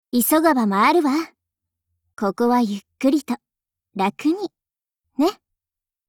碧蓝航线:U-410语音